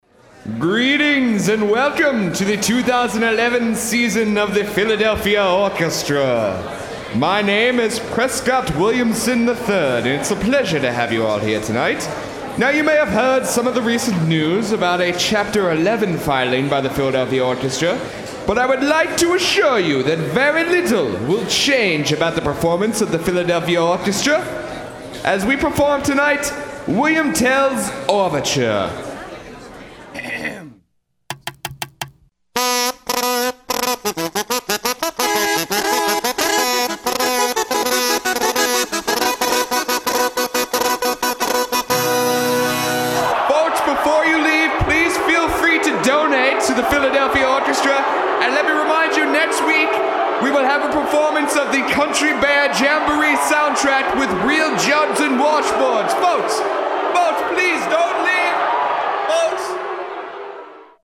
They say the quality of the performances will not suffer, but after hearing this weekend’s performance, I have to disagree.